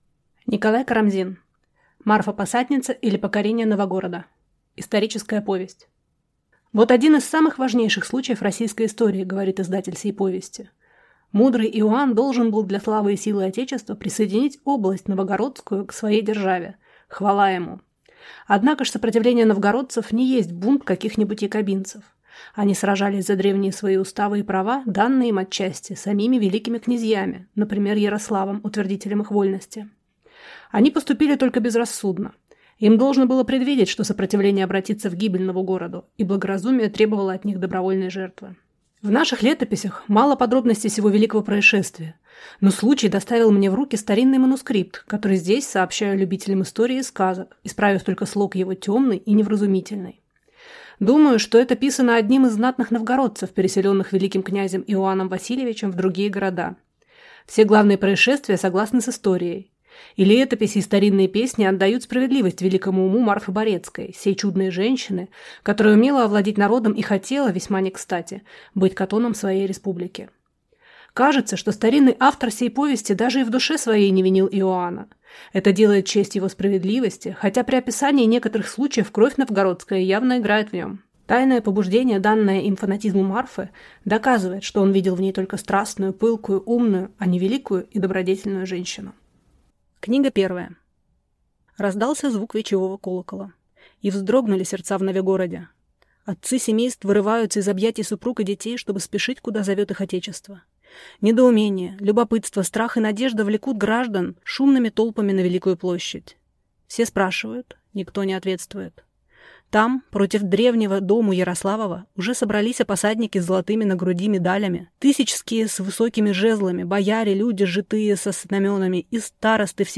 Аудиокнига Марфа-Посадница, или Покорение Новагорода | Библиотека аудиокниг